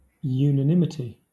Ääntäminen
IPA : /ˌjuːnəˈnɪmɪti/